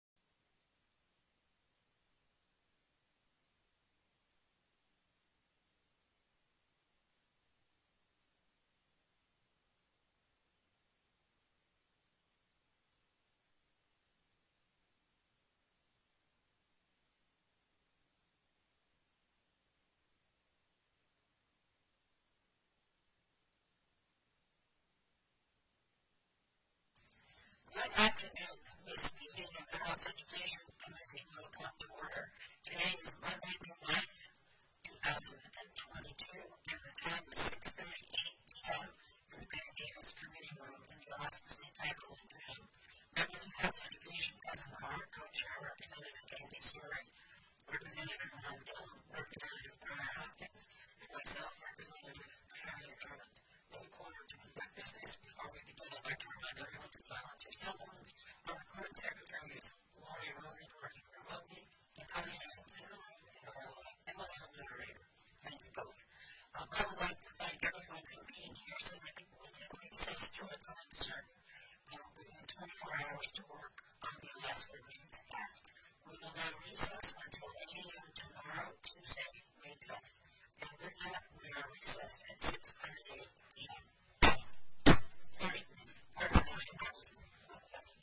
The audio recordings are captured by our records offices as the official record of the meeting and will have more accurate timestamps.
+ Bills Previously Heard/Scheduled TELECONFERENCED